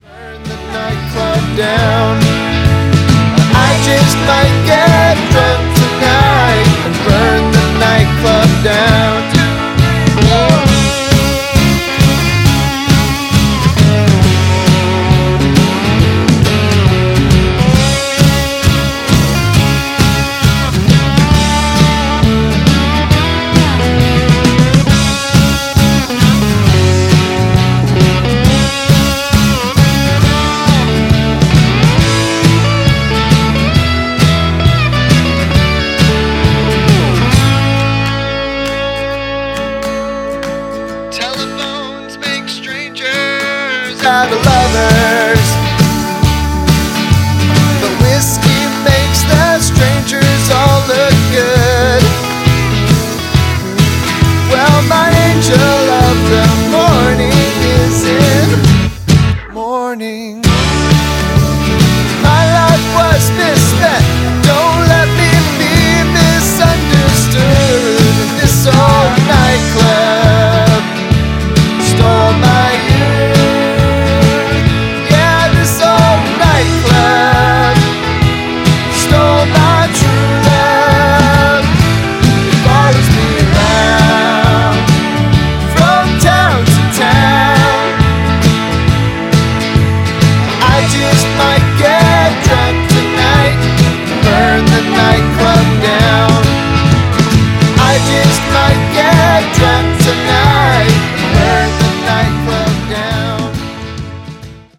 Alt-Country, Americana, Folk